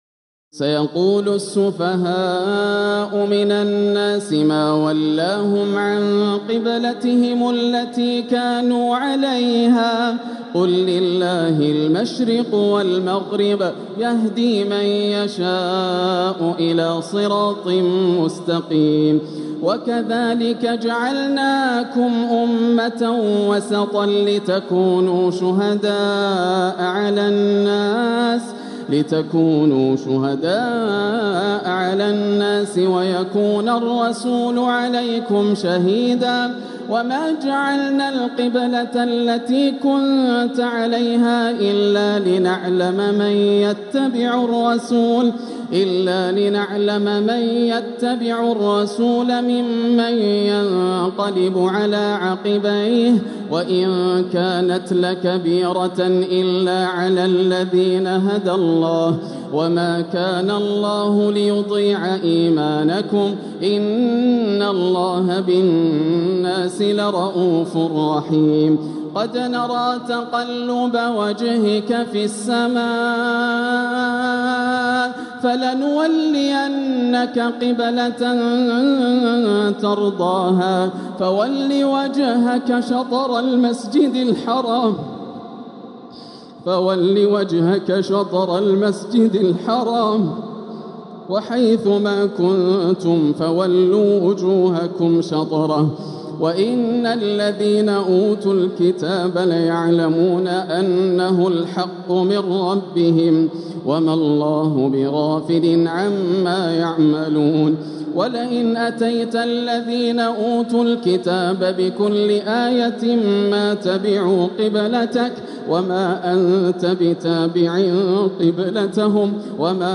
مقتطفات مرئية من محراب الحرم المكي من ليالي التراويح للشيخ ياسر الدوسري رمضان 1446هـ > الليالي الكاملة > رمضان 1446 هـ > التراويح - تلاوات ياسر الدوسري